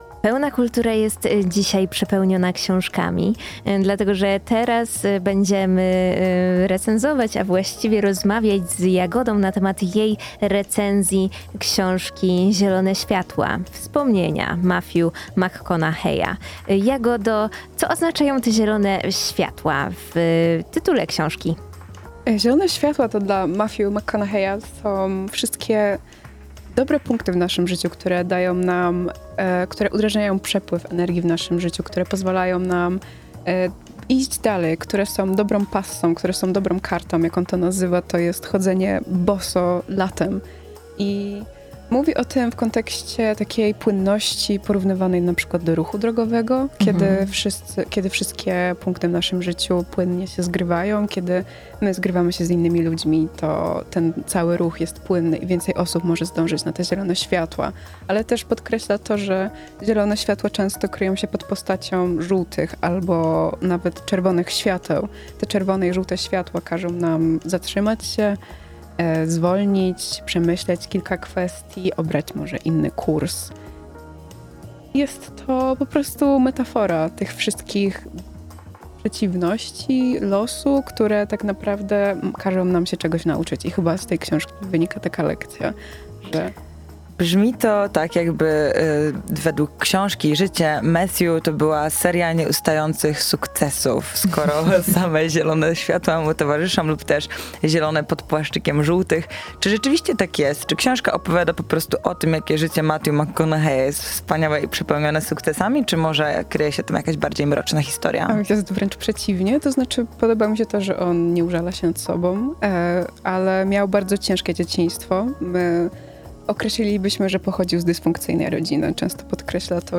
W rozmowie